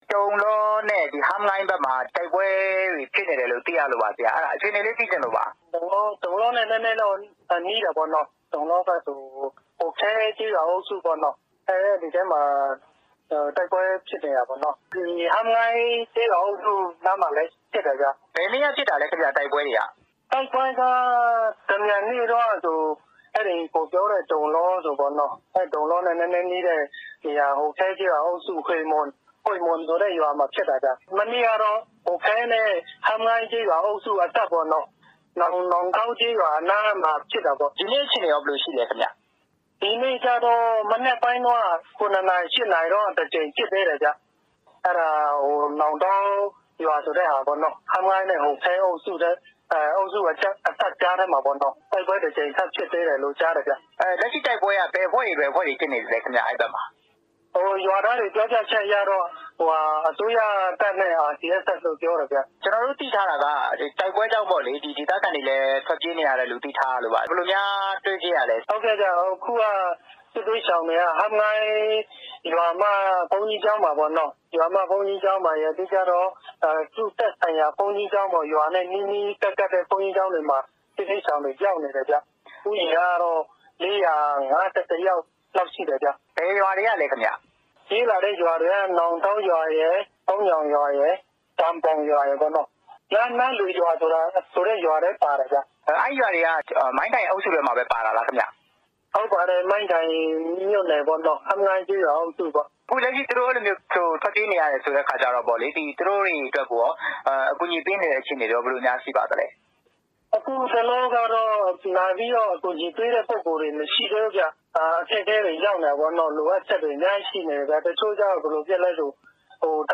ဆက်သွယ်မေးမြန်းထားပါတယ်